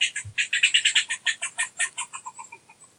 sounds_squirrel_03.ogg